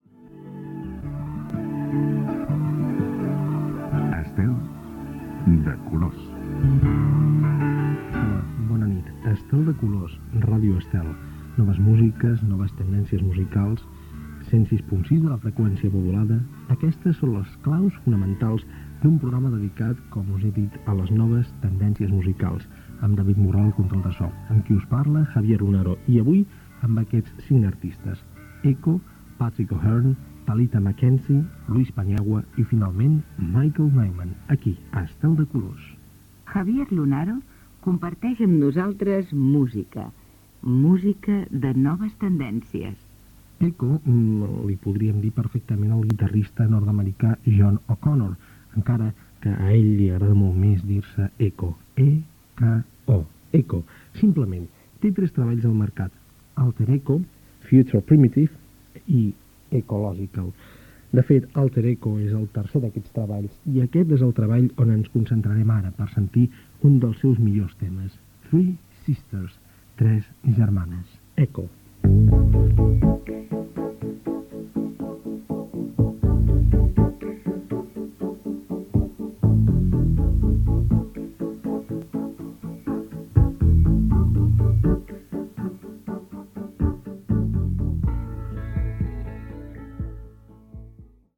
Careta, presentació, equip, artistes que es programaran, indicatiu del programa i presentació del primer tema musical
Musical
FM